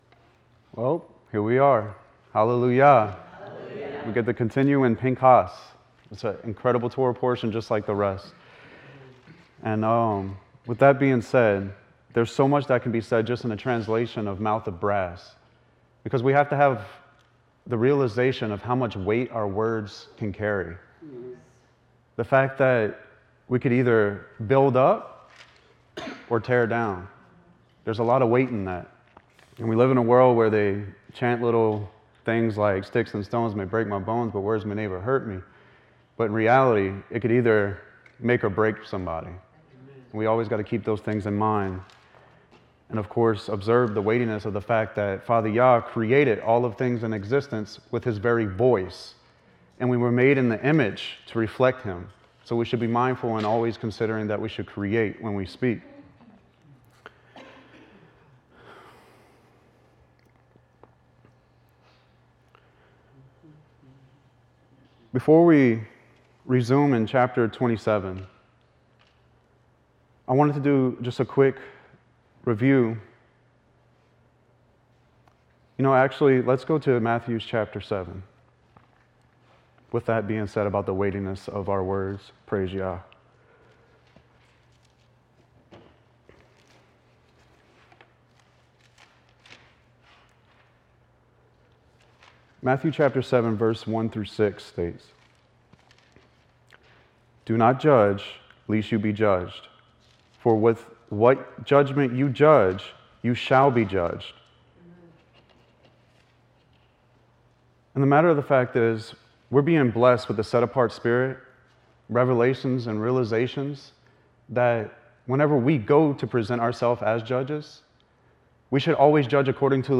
Torah Teachings – Pin’has Part 3